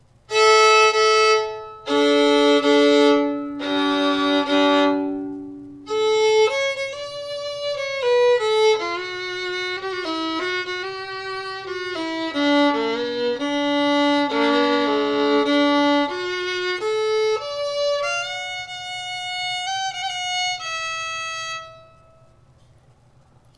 New Handmade Violin / Fiddle Outfit with case & bow - $425.00
I would classify this one as loud in volume, with a moderately bright and even tone quality.